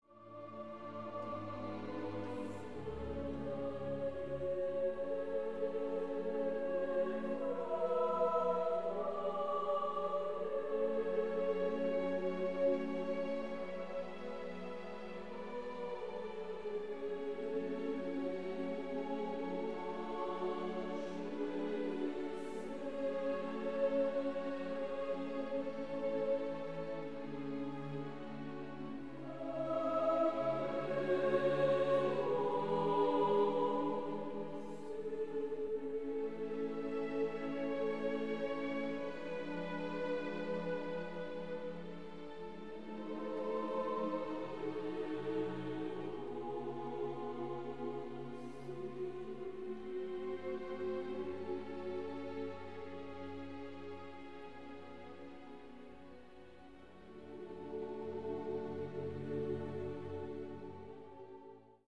Franck redemption choeur des anges.mp3